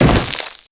SPLAT_Generic_03_mono.wav